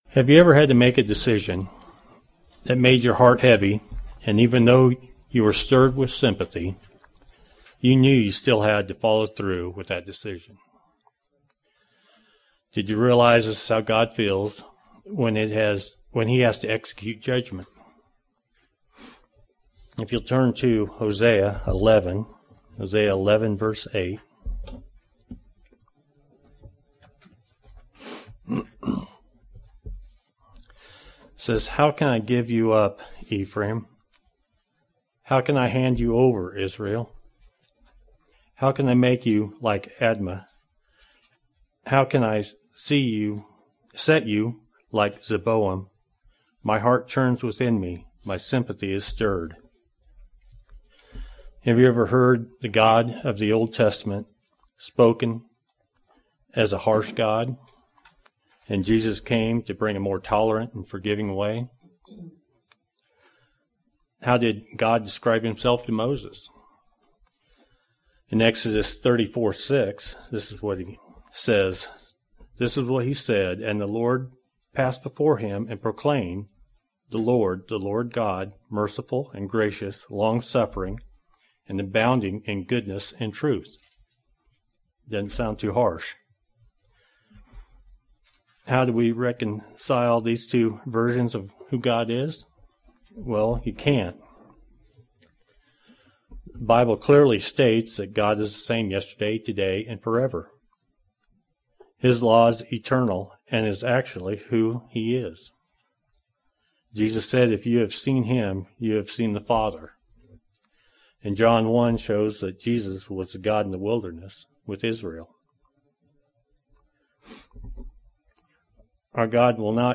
Sermons
Given in Northwest Arkansas